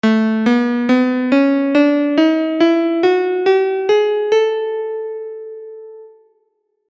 The bright mode of Blackwood[10] in 15edo